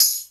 HR16B TAMB.wav